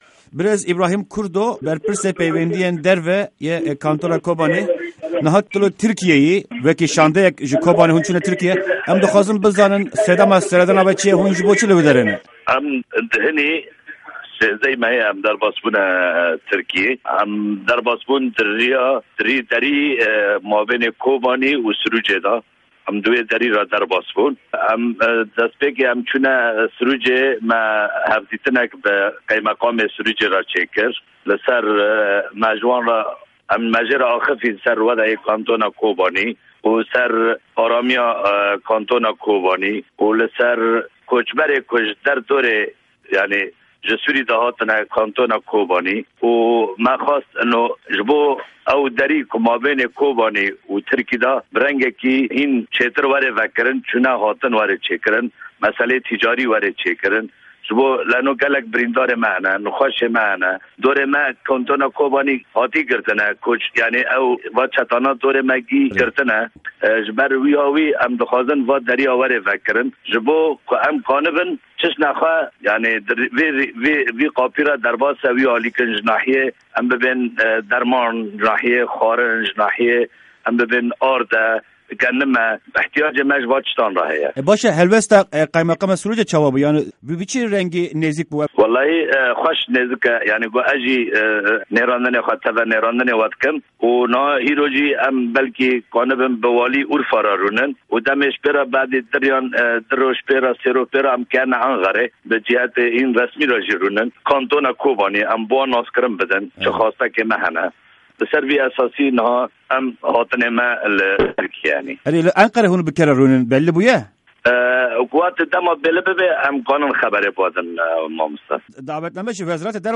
Di hevpeyvîna Dengê Amerîka de